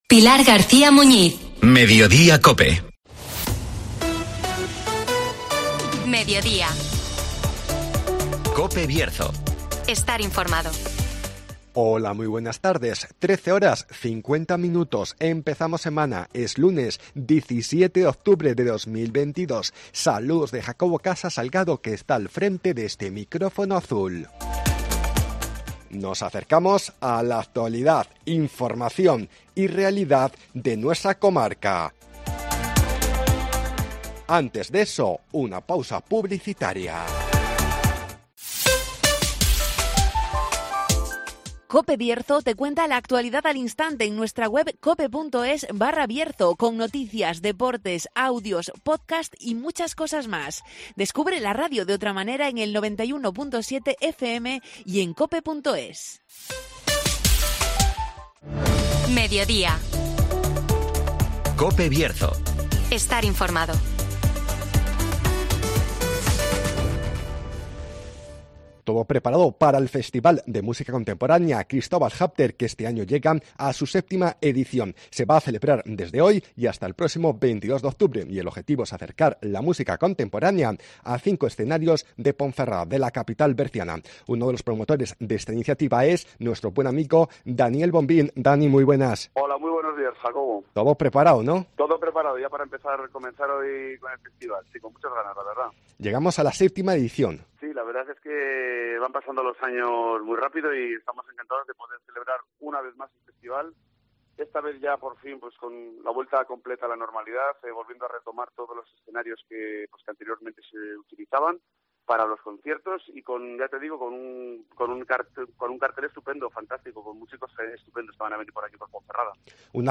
Todo preparado para la séptima edición del festival Cristóbal Halffter (Entrevista